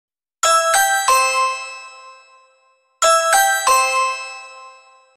Sound Buttons: Sound Buttons View : Ring Doorbell Sound
Ring_Doorbell_Sound.mp3